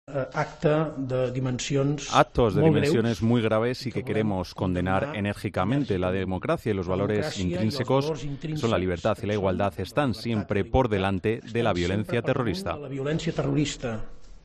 Puigdemont ha comparecido en la Generalitat ante los medios acompañado de su vicepresidente, Oriol Junqueras, y una alcaldesa de Barcelona, Ada Colau, que no ha podido contener las lágrimas en su intervención posterior.